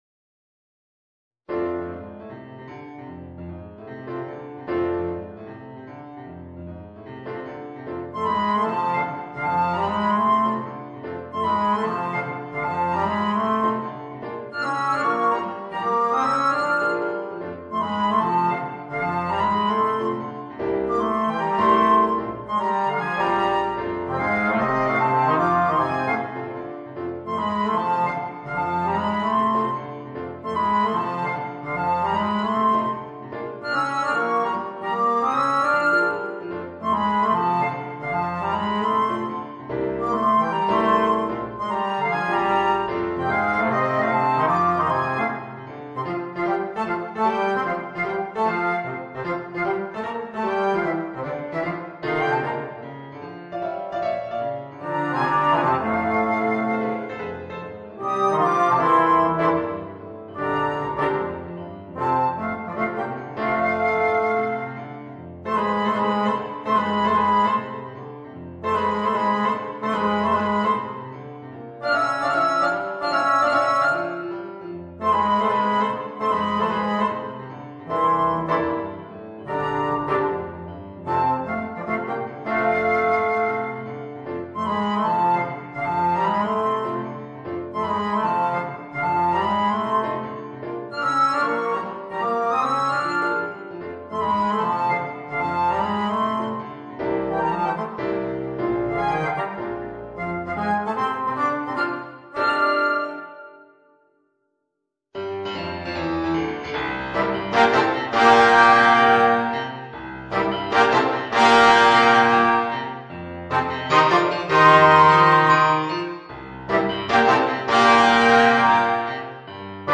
Voicing: Flute, Trombone w/ Audio